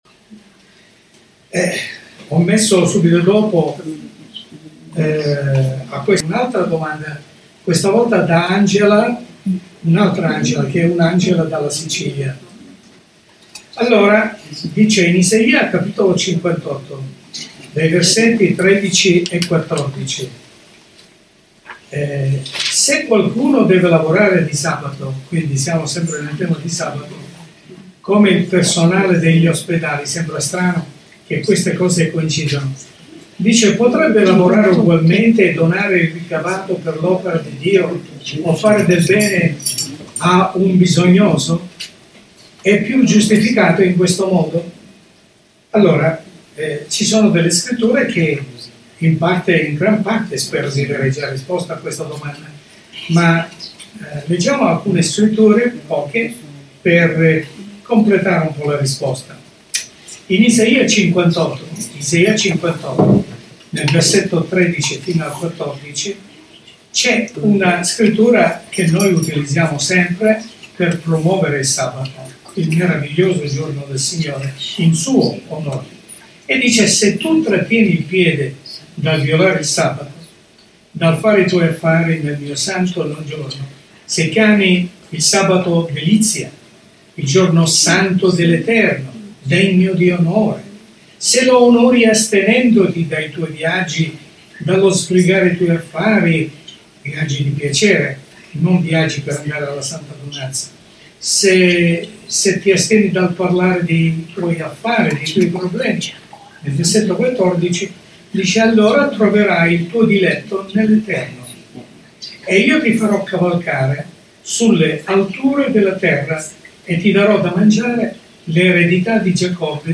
Studio Biblico